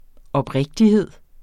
Udtale [ ʌbˈʁεgdiˌheðˀ ]